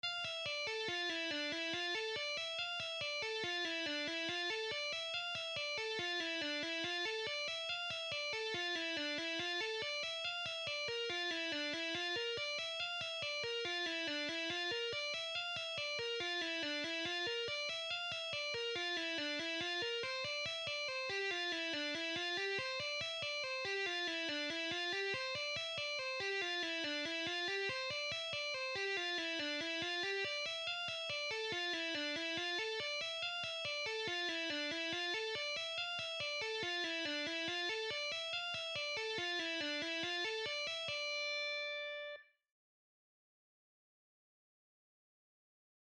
Lesson 5: Symphony X / Michael Romeo Sea Of Lies Tapping
40% Speed:
Exercise-5-Slow-An-Advance-Tapping-Lick-Michael-Romeo-Symphony-X-Sea-Of-Lies1-2.mp3